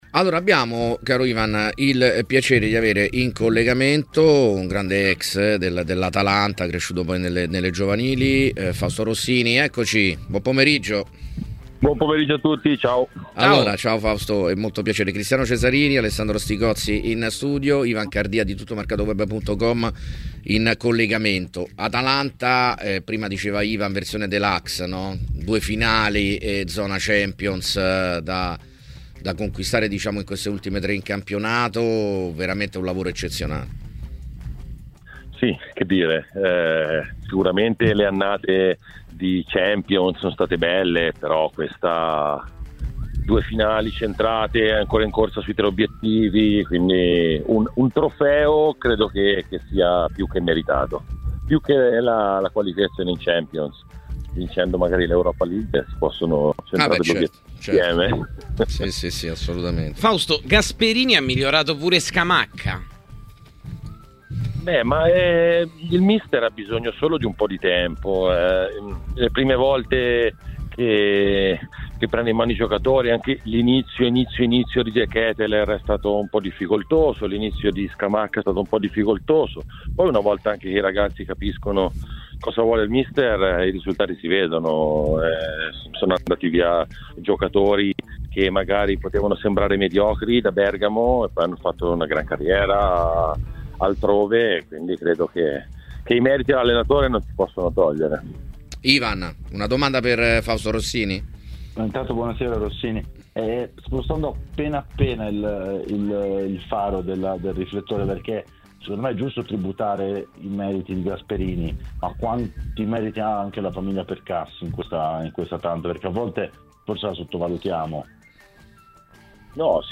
Nella serata di Tmw Radio, all’interno di 'Piazza Affari', è intervenuto l’ex attaccante.